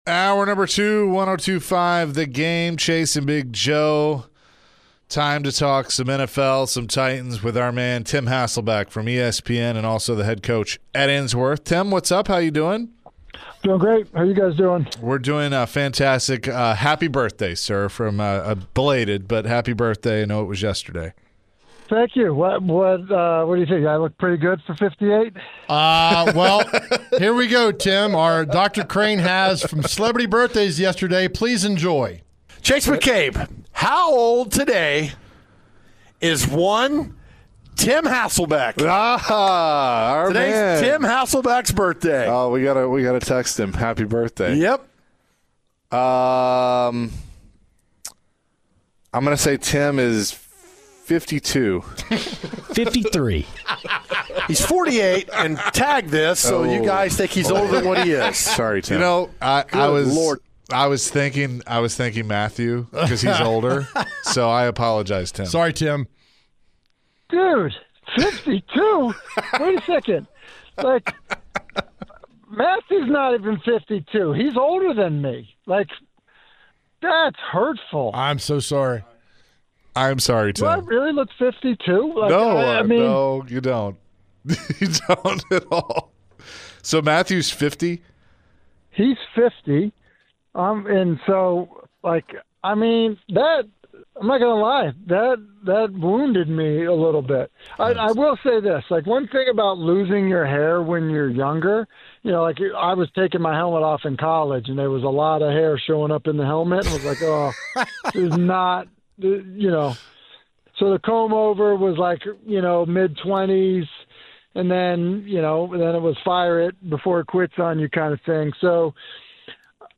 chatted with ESPN's NFL analyst Tim Hasselbeck